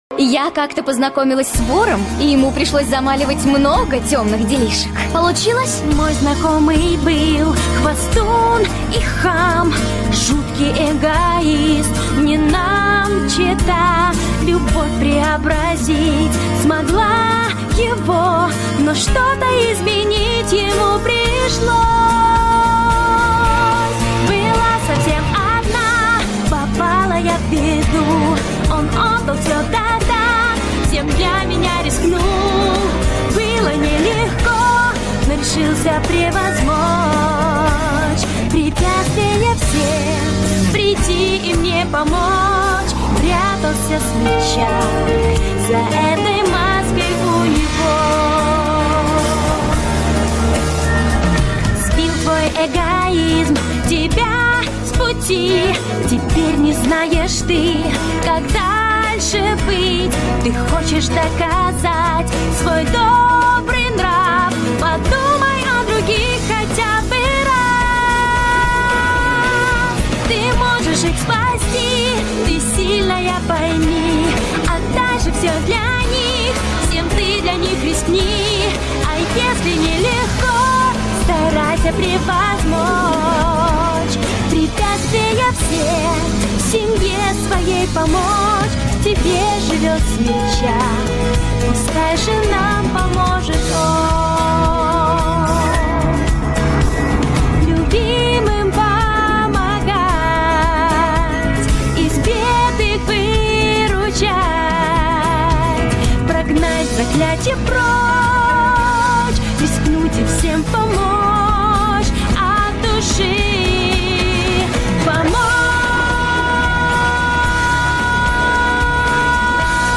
• Жанр: Детские песни
🎶 Детские песни / Песни из мультфильмов